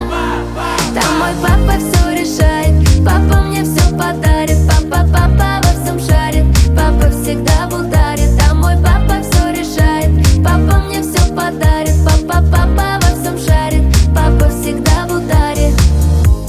поп
добрые , милые